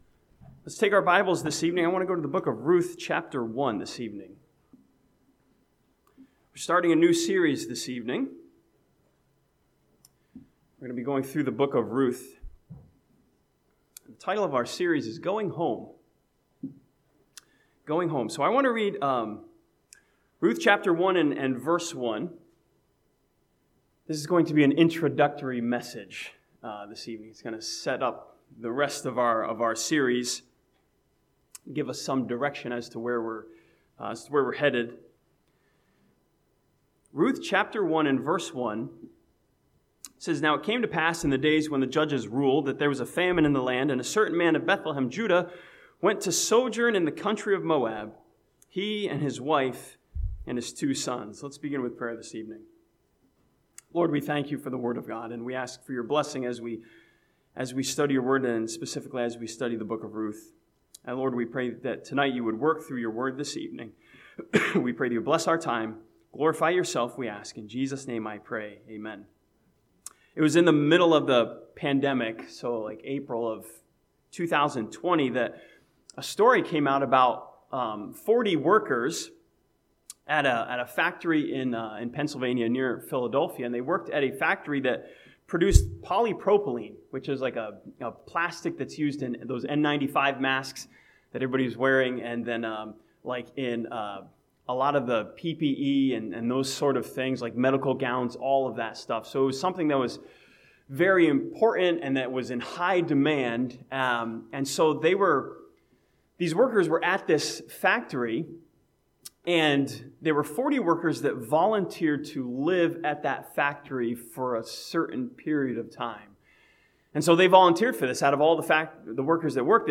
Sunday PM